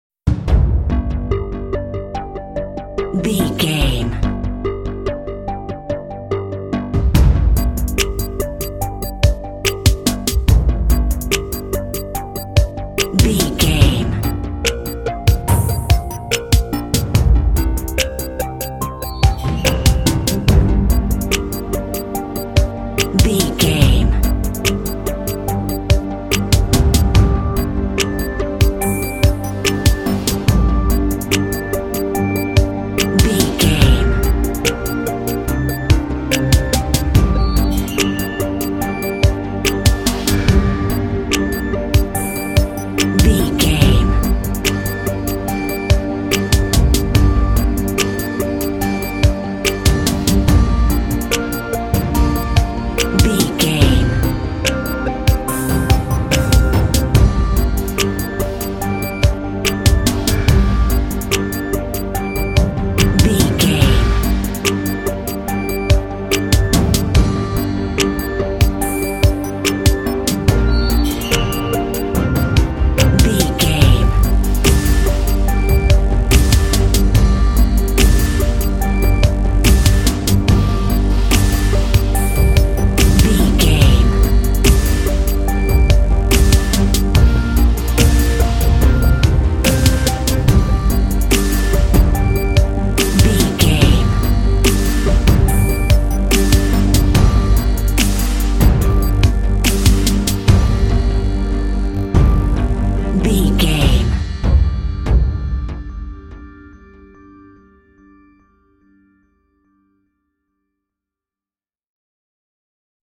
Thriller
Aeolian/Minor
tension
scary
synthesiser
drums
strings
contemporary underscore